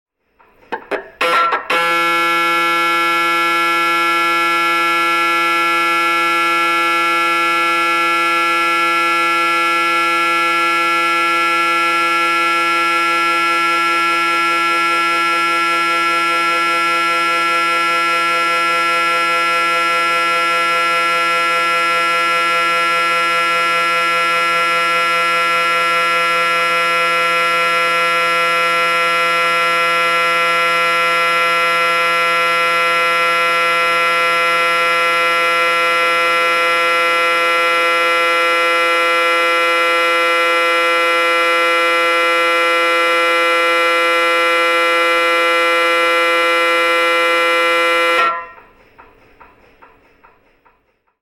На этой странице представлены звуки работы аппарата МРТ – от ритмичных постукиваний до гудения разной интенсивности.
Магнитно-резонансный томограф: ускоренное сканирование с применением эхо-сигнала